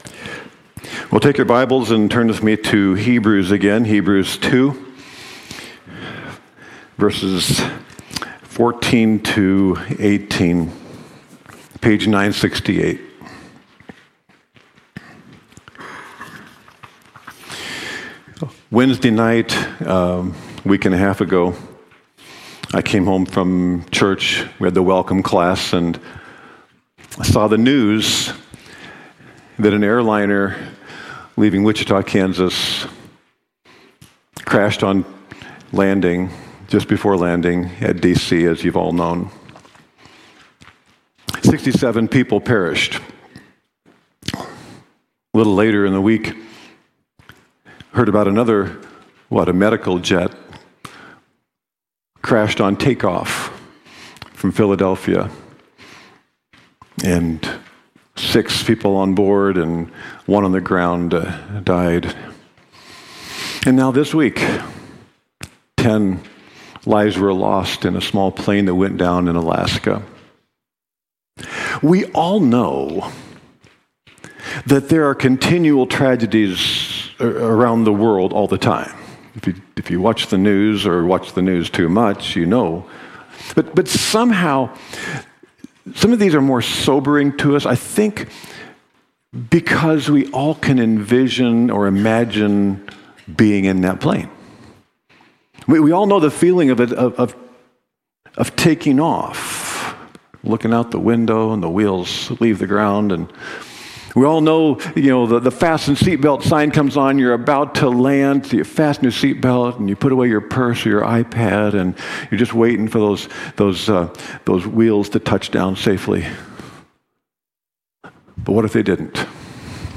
Open Door Bible Church sermons
Weekly messages examine books of the Bible as well as specific topics at Open Door Bible Church in Port Washington, WI.